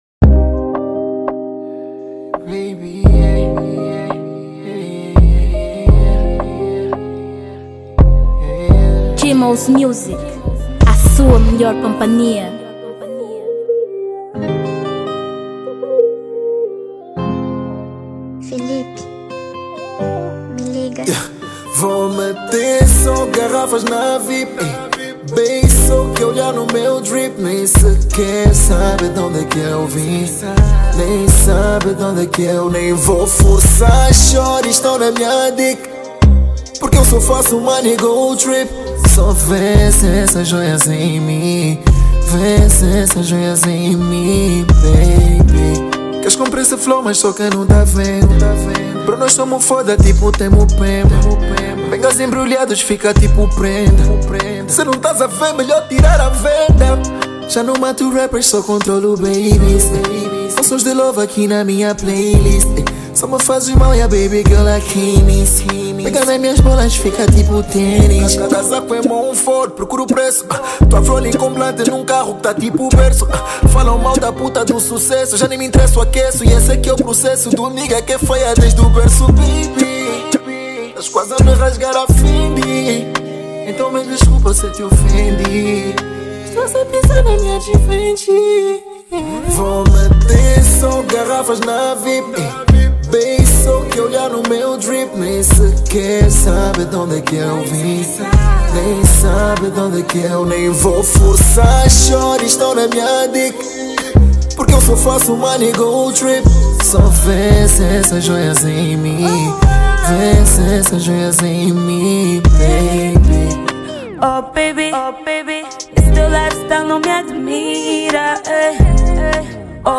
uma faixa do gênero Funk